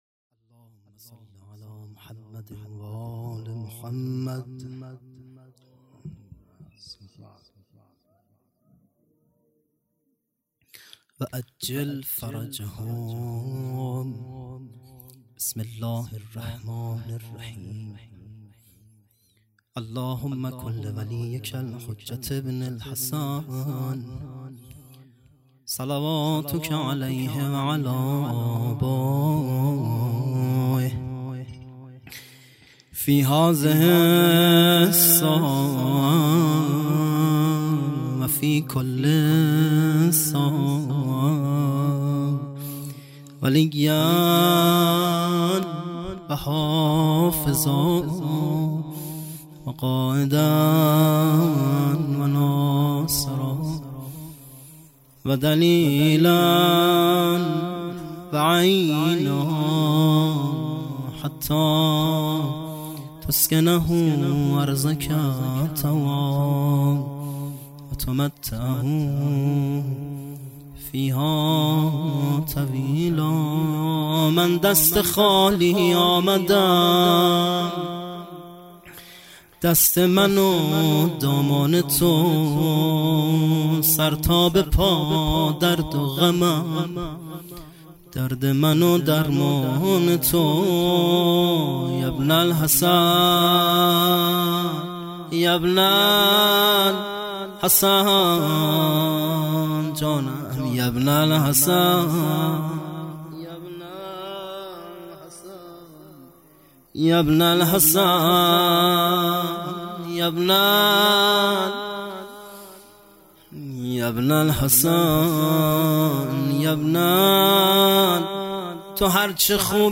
خیمه گاه - هیئت بچه های فاطمه (س) - سخنرانی